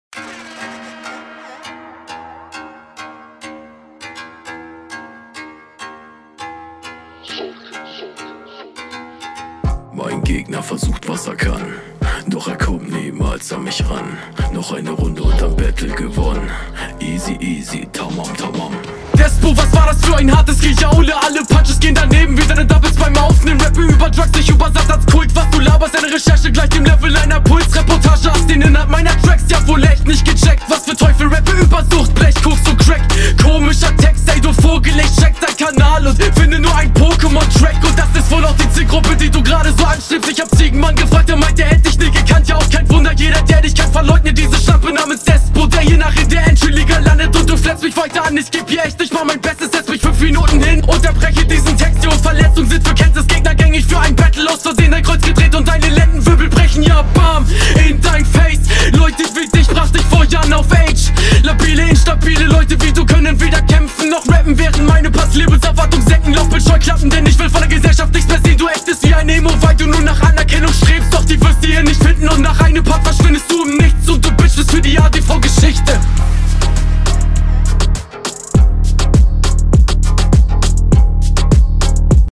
oooh, was passiert hier. dein sound ist echt richtig stark. kommst direkt arg gut auf …